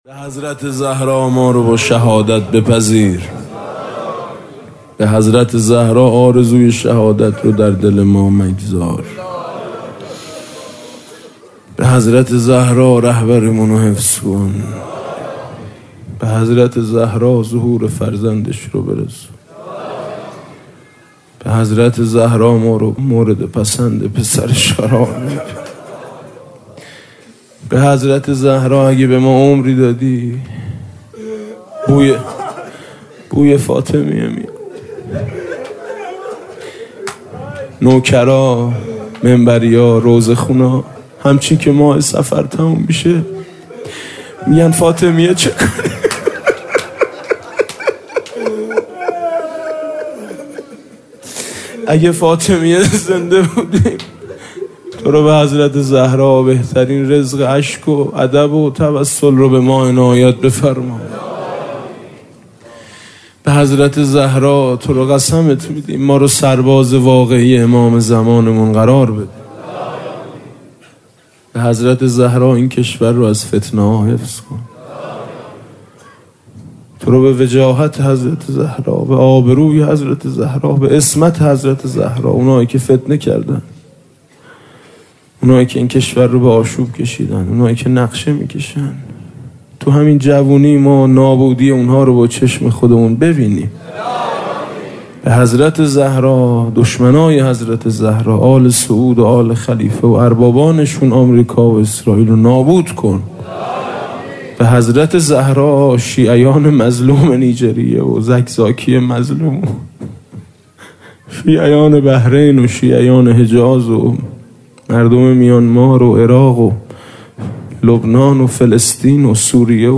5اذر98-هیات میثاق باشهدا - خدایا به حق حضرت زهرا